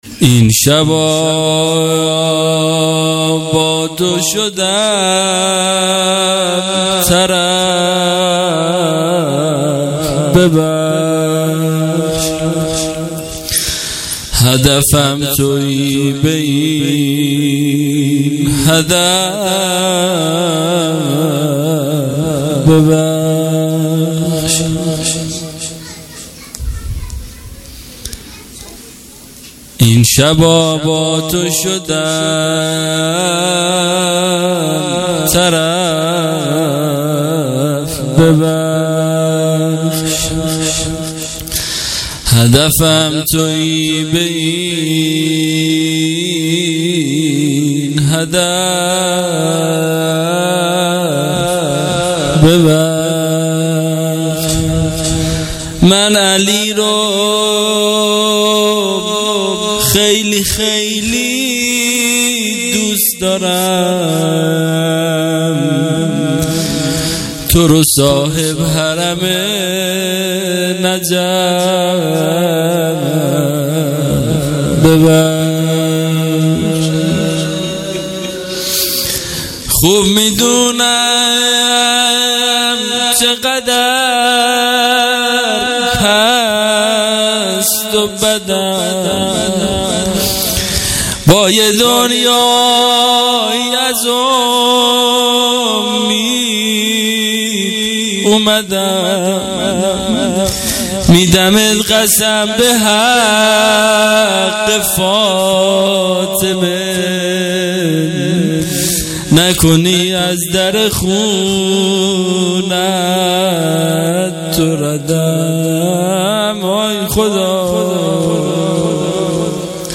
مراسم شب چهارم ماه رمضان 98